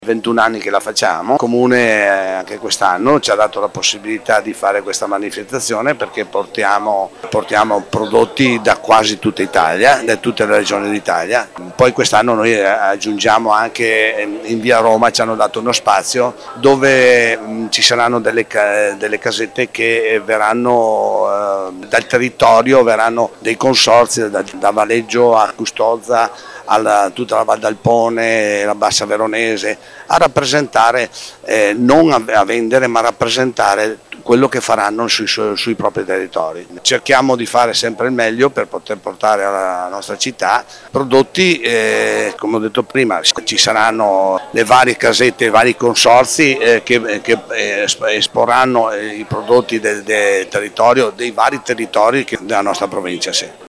ha raccolto le dichiarazioni nella giornata di presentazione dell’evento: